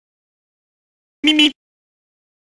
Cartoon Meep Meep Sound
cartoon